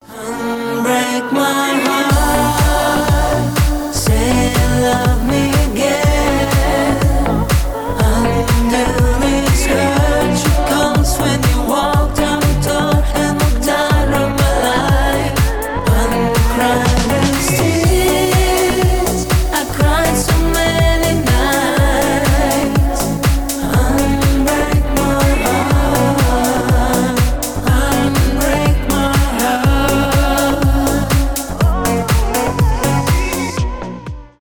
deep house
club house
cover
танцевальные